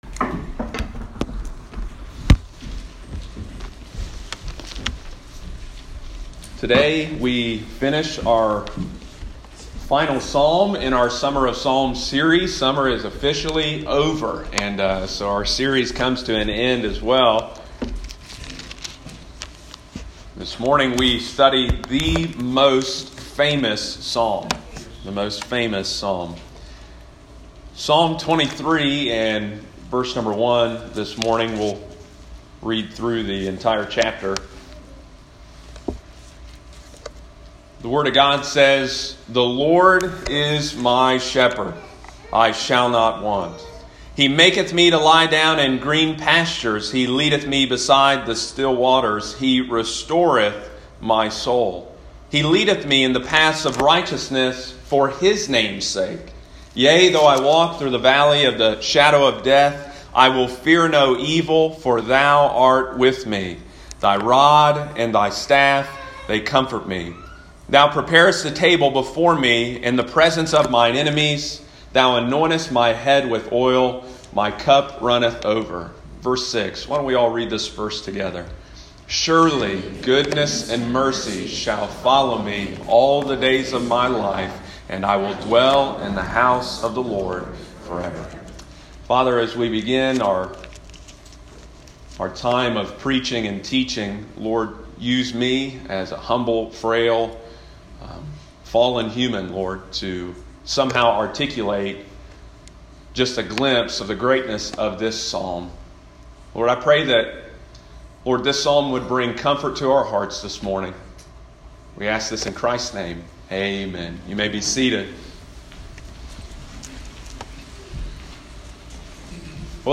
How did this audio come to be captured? Sunday morning, September 27, 2020.